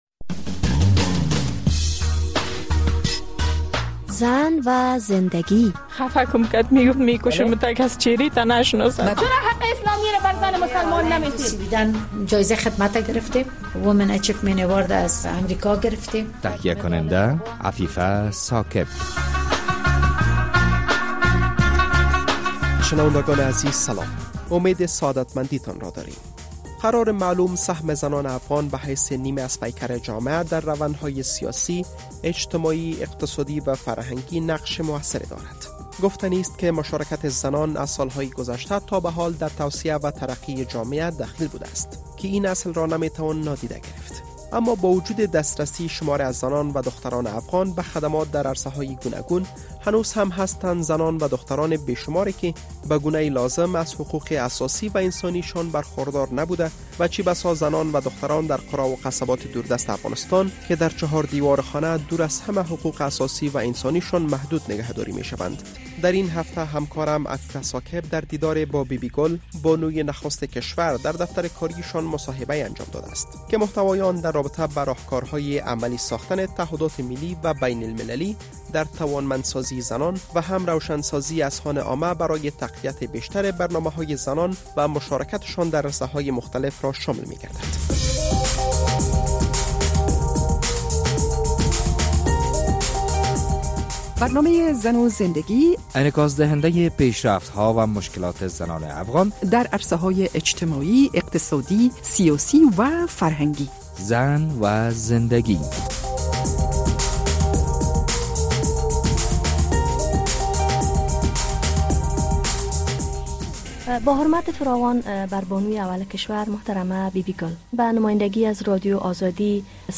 مصاحبه اختصاصی رادیو آزادی با بی بی گل غنی